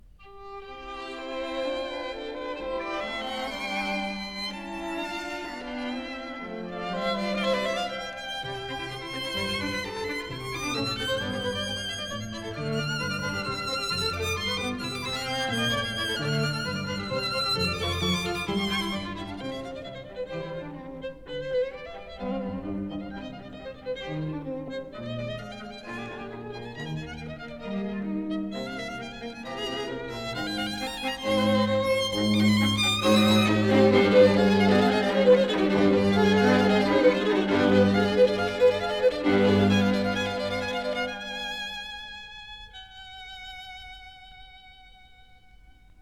violin
viola
cello
Studios,30th Street, New York City